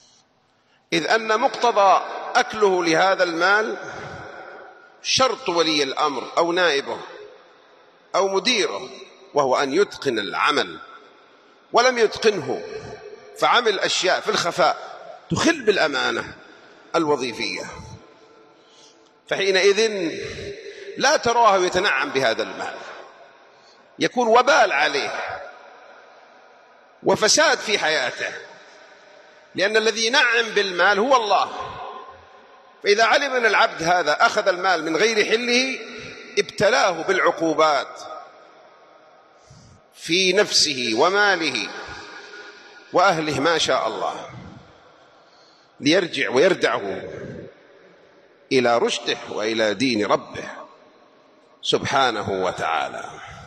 606 [ درر قحطانية ] - الموظف الذي لا يؤدي واجبه الوظيفي في العمل ويخل بذلك يبتلى بالعقوبات من الله { خطبة الجمعة } .